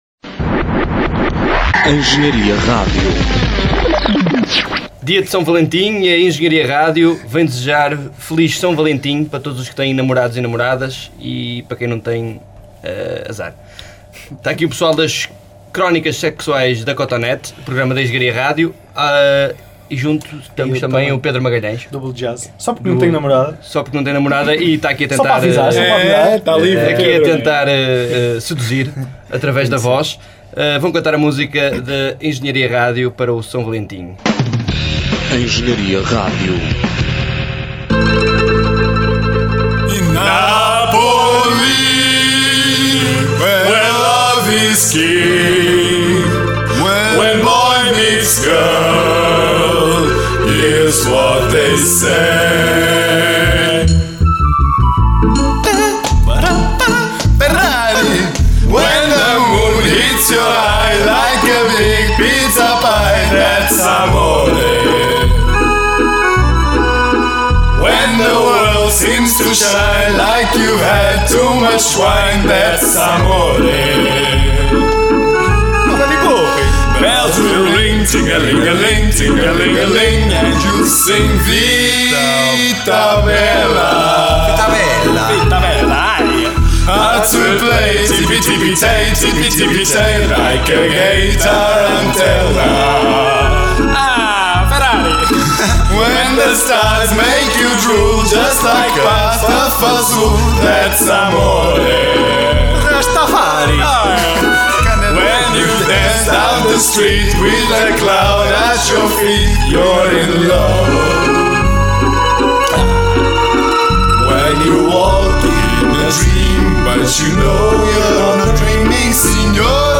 juntou-se aos frescos e fofos membros das Crónicas Sexuais de uma Cotonete, para cantar a música de S. Valentim da ER.